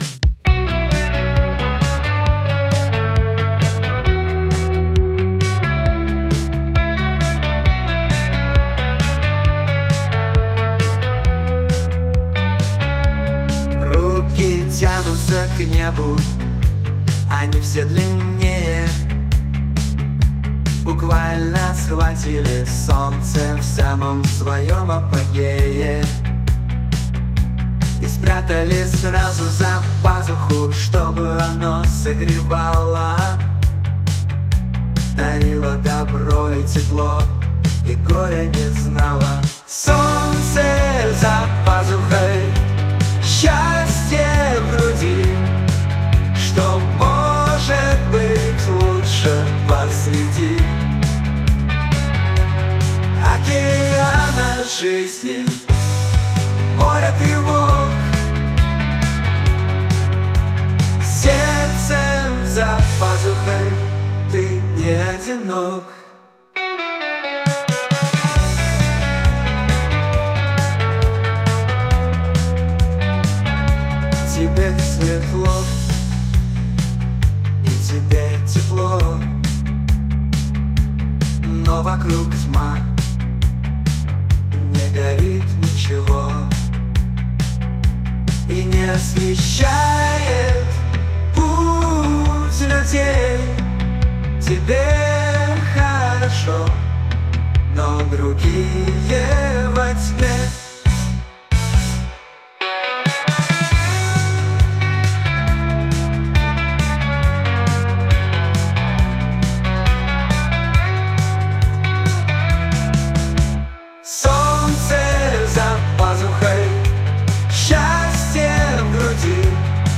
art-rock, post-punk, drums, reverb guitars, synth,